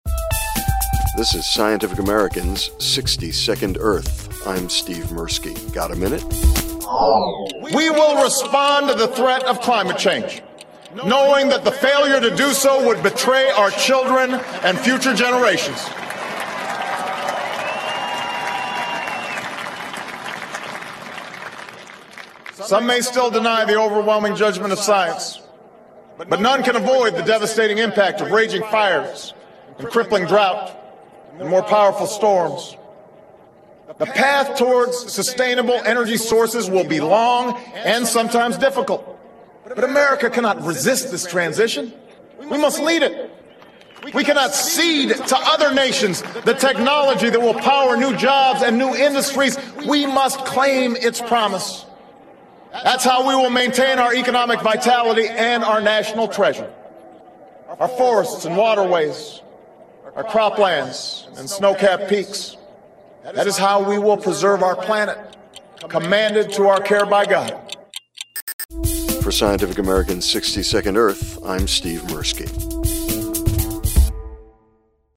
From Pres. Barack Obama’s second inaugural address, January 21, 2013: